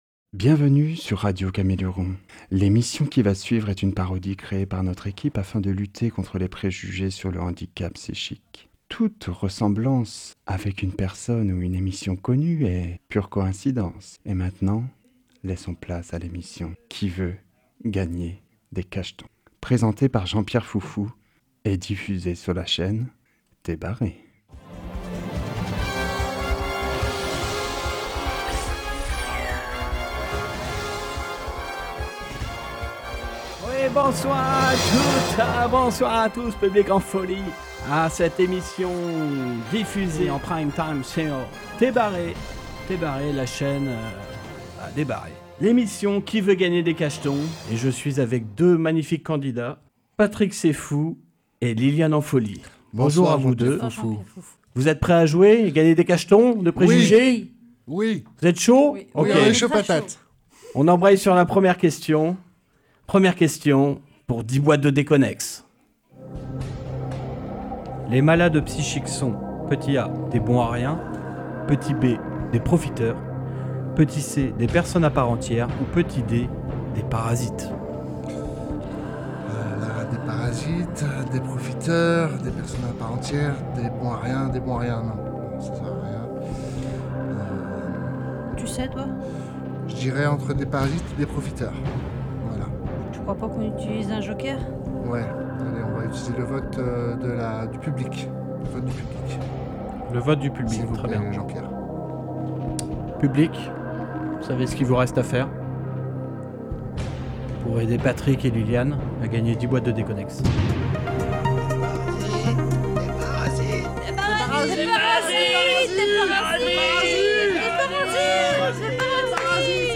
Cette année l’équipe de Radio Caméléon a participé à un concours de création sonore pour lutter contre les préjugés sur le handicap psychique. C’est donc dans le cadre du festival " Sans œillère on s’entend mieux " que le groupe a réalisé une parodie d’une célèbre émission télévisée qui a été intitulée " Qui veut gagner des cachetons ? ".